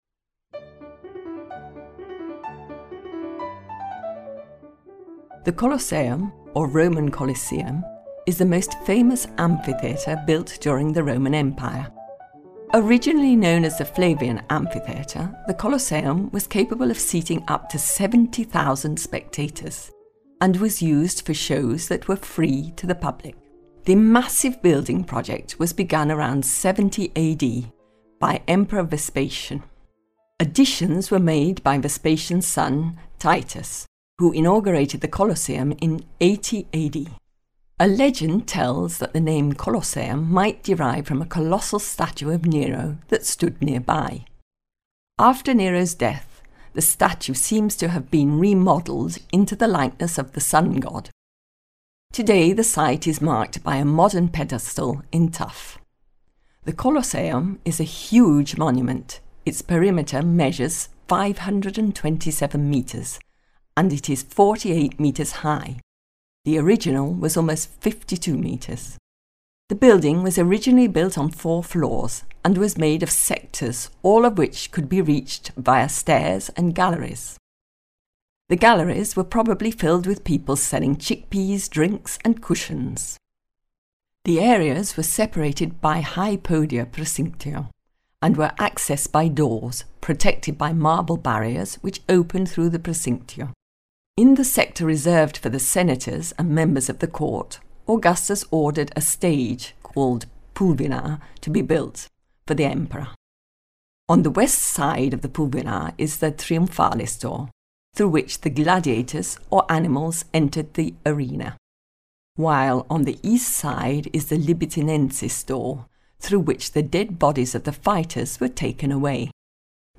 Audio Guide Rome – The Colosseum